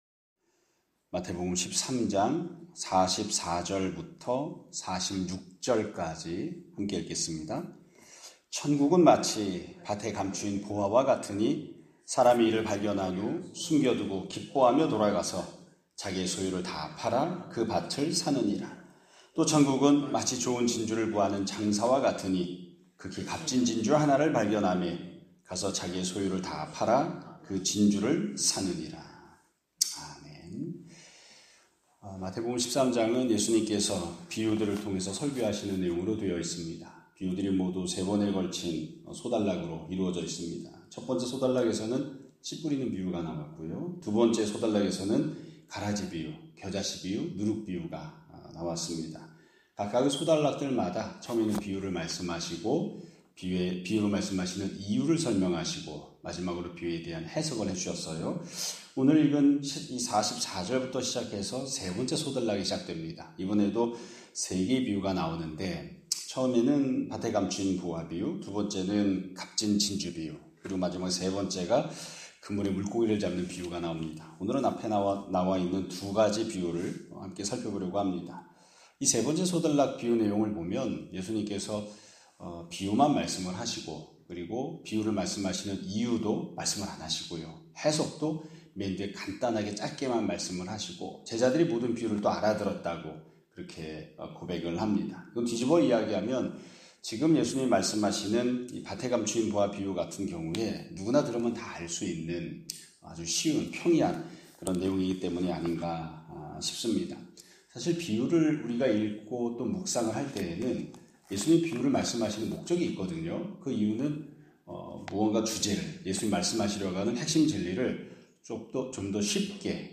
2025년 10월 2일 (목요일) <아침예배> 설교입니다.